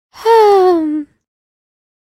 shopkeep-sigh.ogg.mp3